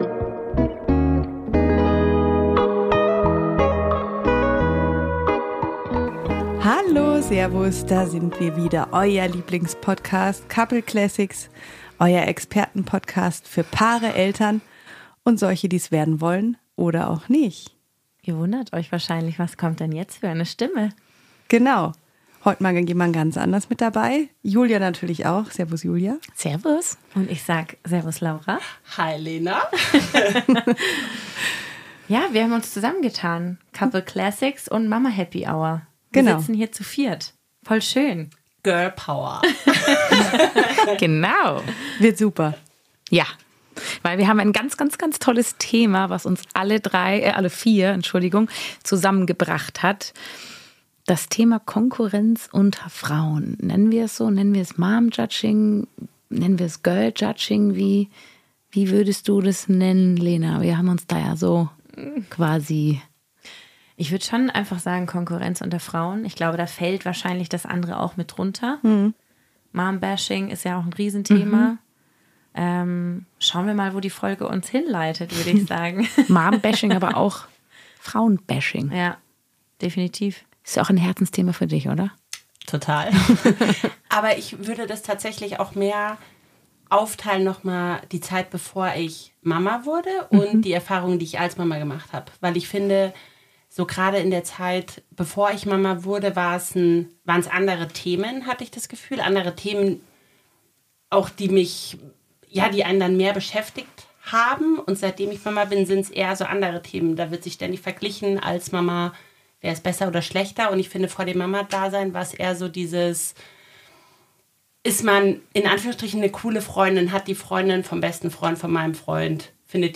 Vier Stimmen, ein ehrliches Thema: Konkurrenz unter Frauen. Ob Mom-Bashing, Girl-Judging oder der ewige Vergleich – wir sprechen darüber, wie gesellschaftliche Strukturen und persönliche Erfahrungen diese Dynamik prägen.